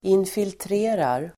Ladda ner uttalet
infiltrera verb, infiltrate Grammatikkommentar: A & x Uttal: [infiltr'e:rar] Böjningar: infiltrerade, infiltrerat, infiltrera, infiltrerar Definition: nästla sig in i (en motståndares organisation) Avledningar: infiltration (infiltration)